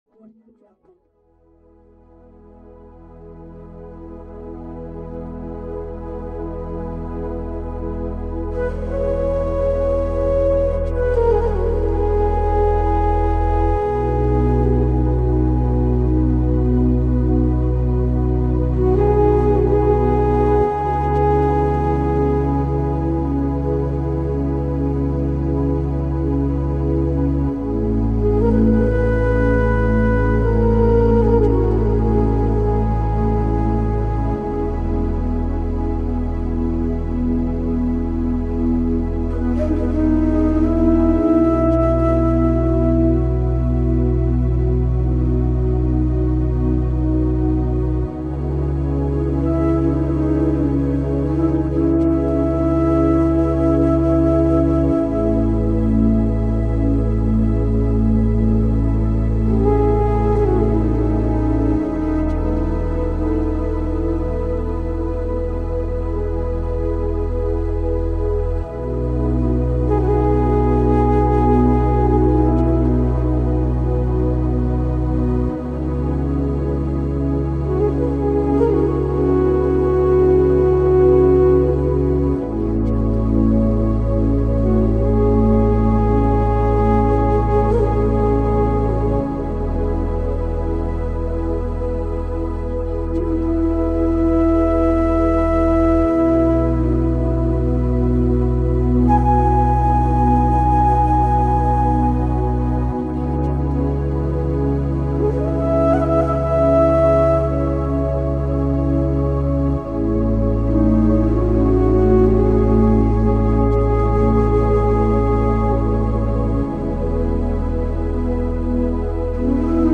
1-Hour Yoga Meditation Soundscape – Clear Negative Energy, Relax Your Mind, and Find Inner Peace
All advertisements are thoughtfully placed only at the beginning of each episode, ensuring you enjoy the complete ambient sounds journey without any interruptions.